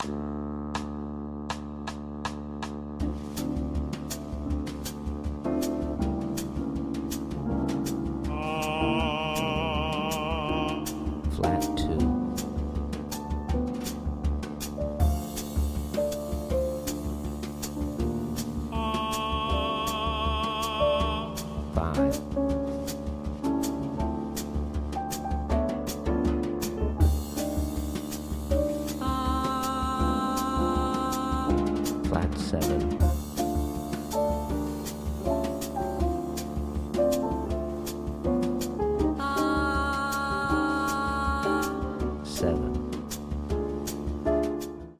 • Listening Exercise With Drone at Medium Tempo